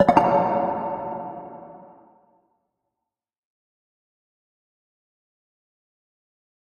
menu-options-click.ogg